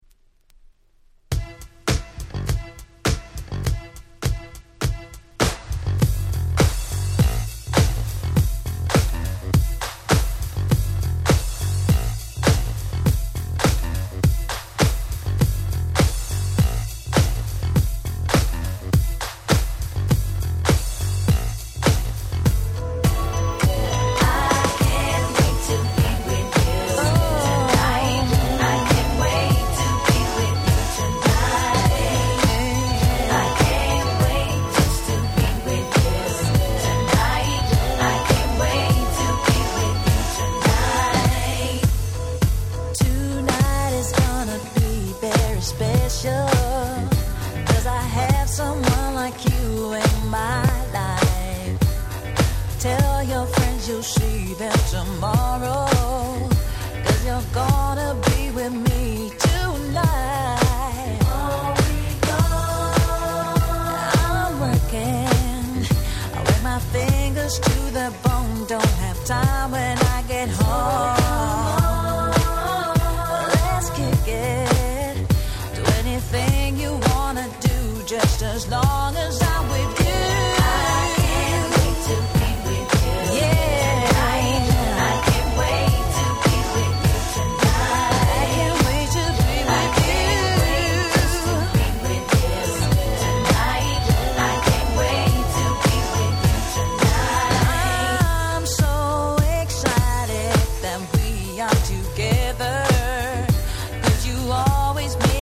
UK R&Bや90's R&Bが好きならど真ん中なはず！！